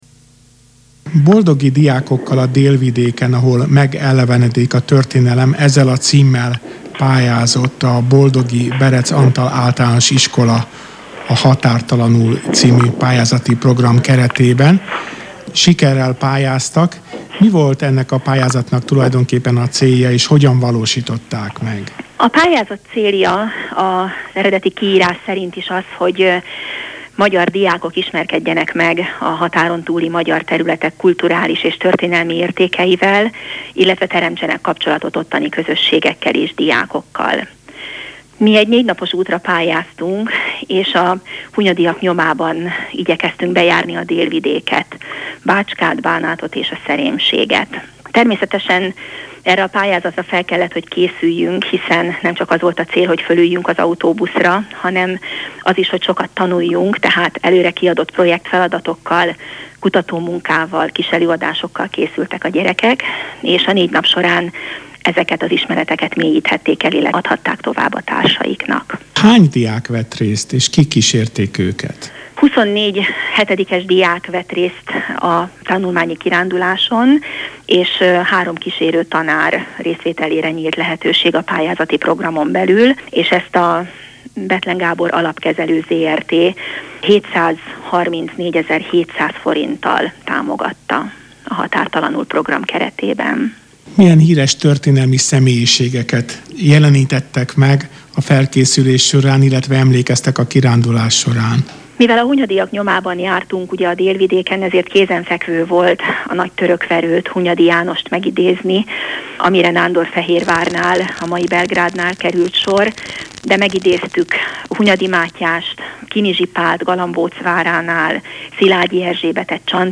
radioriport1.mp3